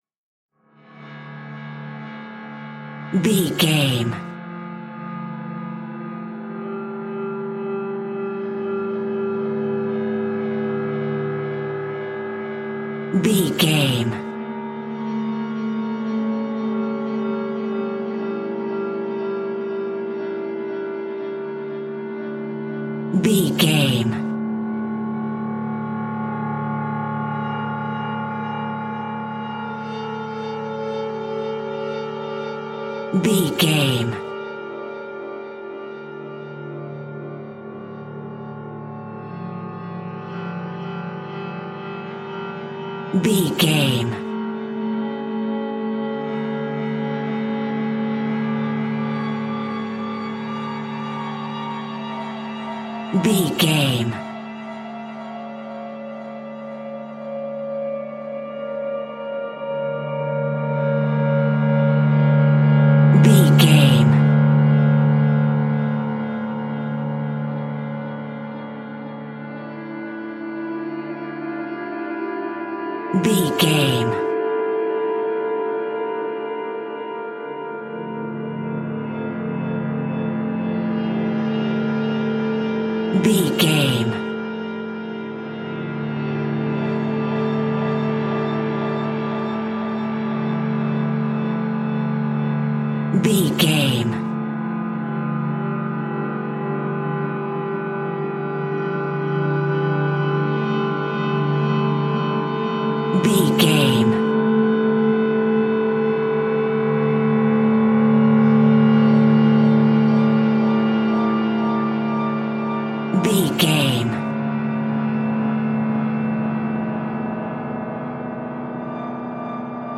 Aeolian/Minor
Slow
tension
ominous
dark
eerie
strings
synthesiser
horror
gongs
taiko drums
timpani